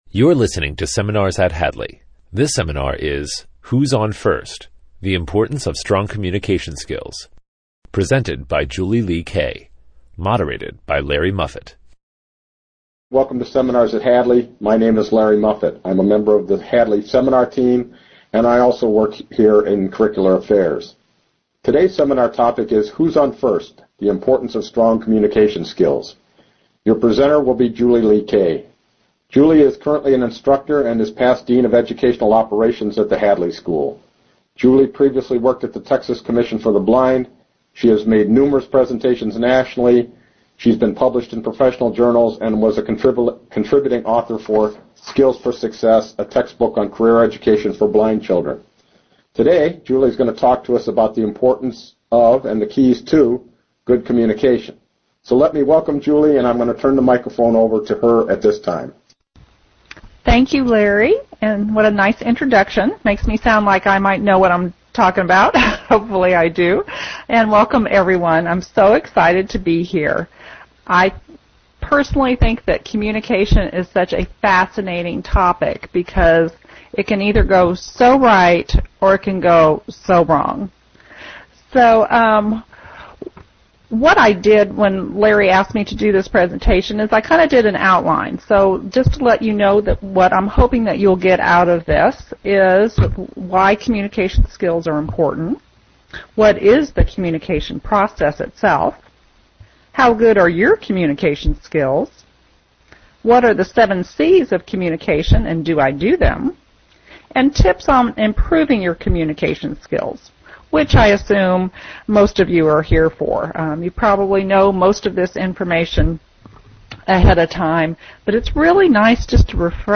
The Importance of Strong Communication Skills - Transcript The Hadley School for the Blind This seminar is presented with the permission of The Hadley School for the Blind.